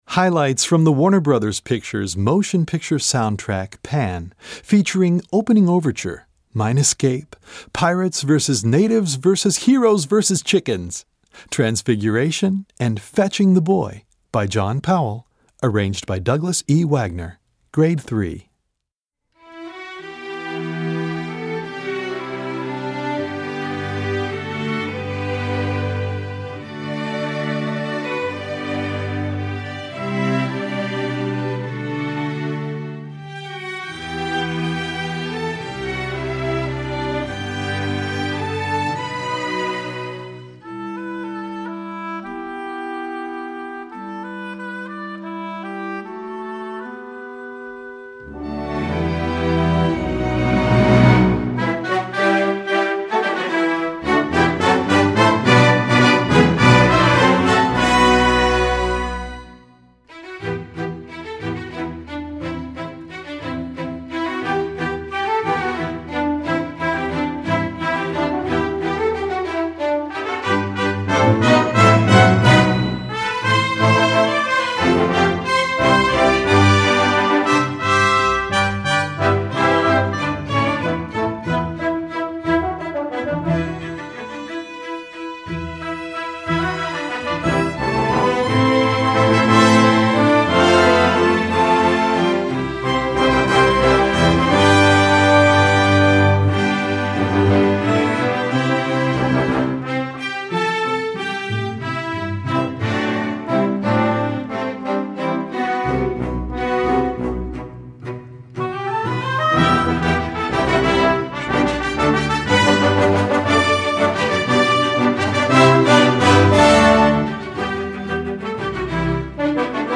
Gattung: Sinfonieorchester
Besetzung: Sinfonieorchester